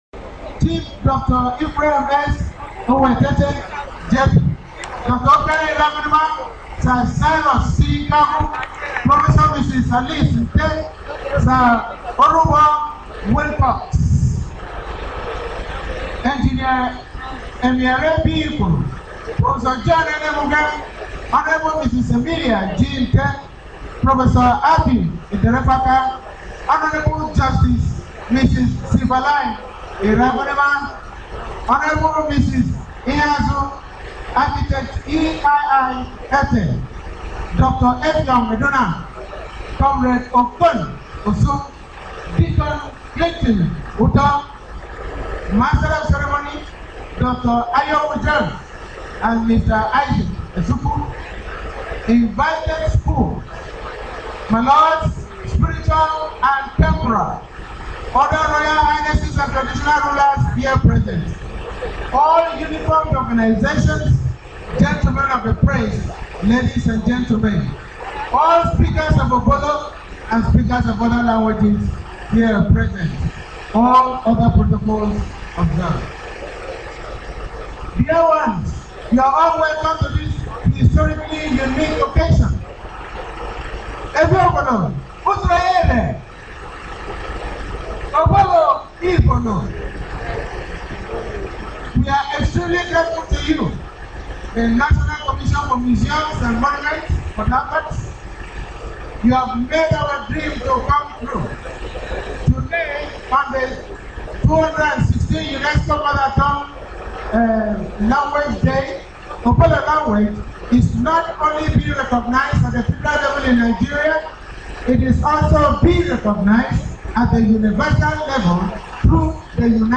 AN ADDRESS PRESENTED AT THE CELEBRATION OF THE UNESCO INTERNATIONAL MOTHER LANGUAGE DAY AT THE NATIONAL MUSEUM, OLD GRA, PORT HARCOURT, RIVERS STATE, ON FEBRUARY 23, 2016